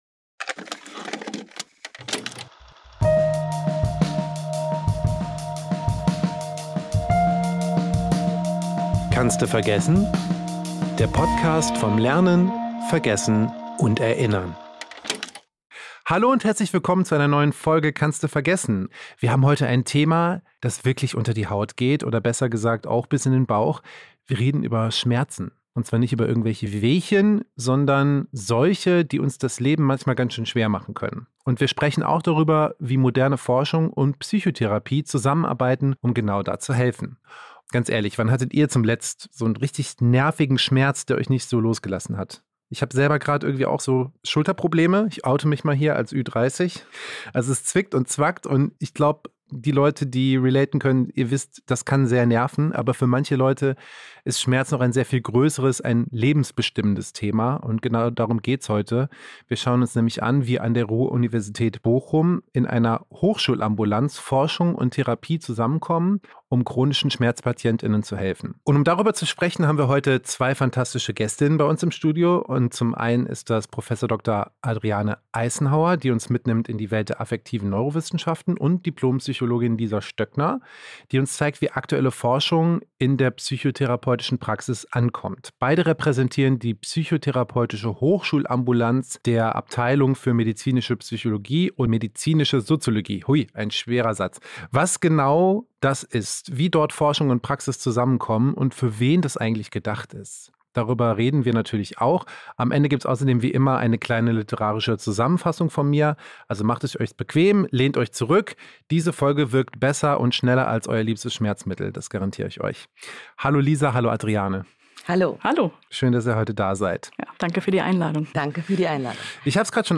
Im Januar 2021 ging es los mit diesem Gesprächsformat. Und jetzt, fünf Jahre und 35 Folgen später, widmen wir uns einem Thema, das auch damals schon viele in unserem Sonderforschungsbereich umgetrieben hat: Dem chronischen Schmerz.